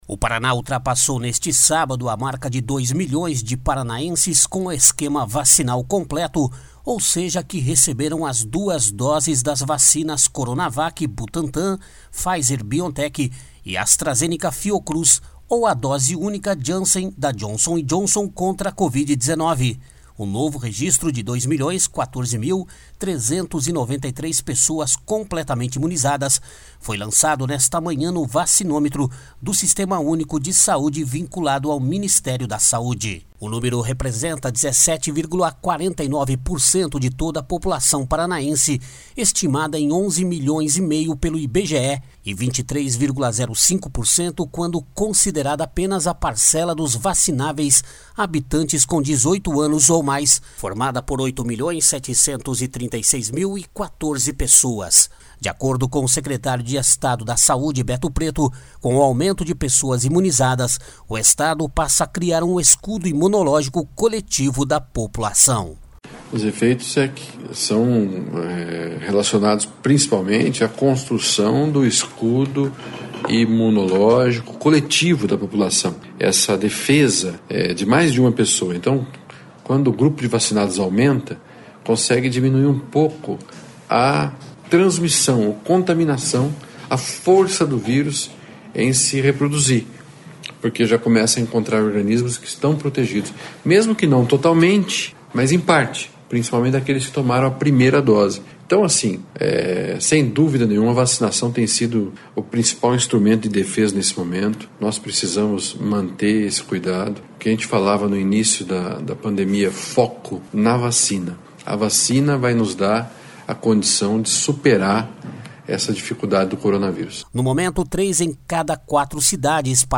De acordo com o secretário de Estado da Saúde, Beto Preto, com o aumento de pessoas imunizadas o Estado passa a criar um escudo imunológico coletivo da população //SONORA BETO PRETO .// No momento, três em cada quatro cidades paranaenses vacinam a população geral na faixa dos 30 anos, reforçando a isonomia entre municípios promovida pela Secretaria estadual desde o início do plano de imunização.